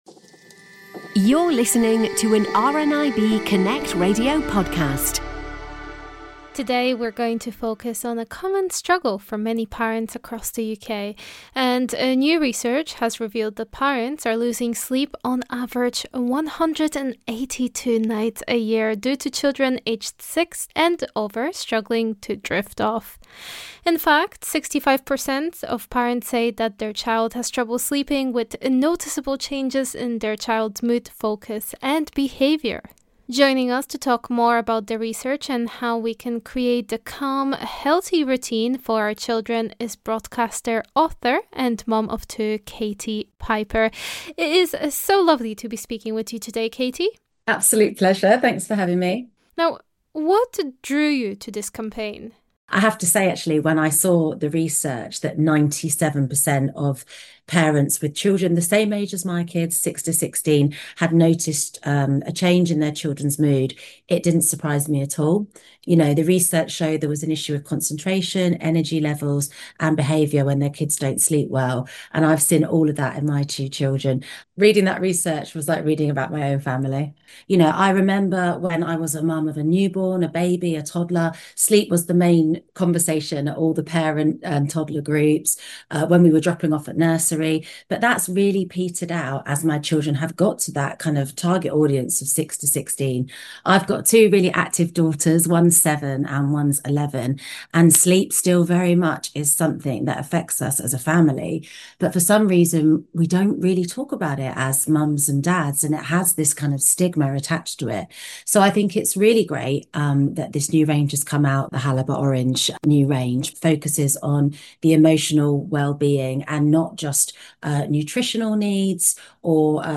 Joining us to talk more about the research and how we can create that calm, healthy routine for our children is broadcaster, author, and mum of two, Katie Piper.